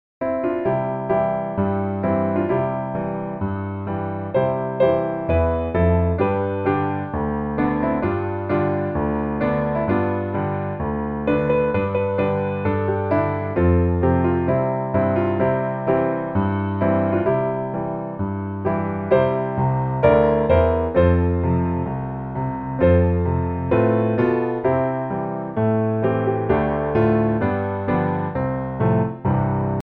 C Majeur